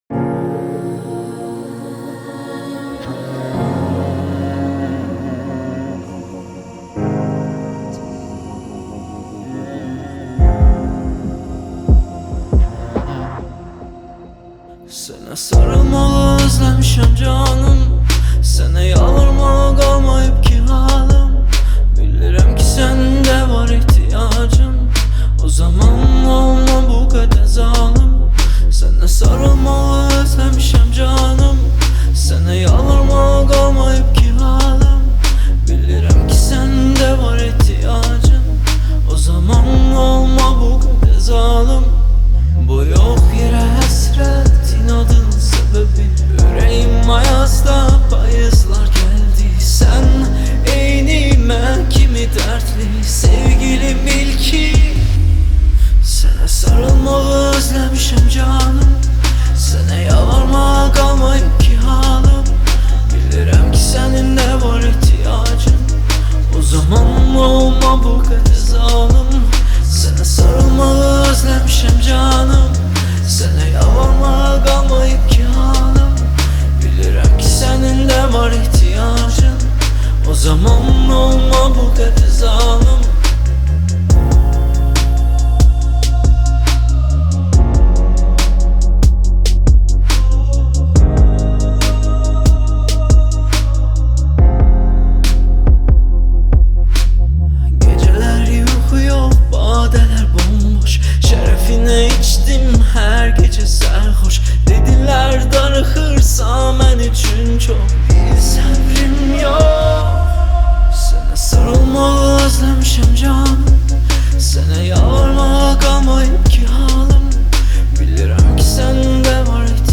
آهنگ آذری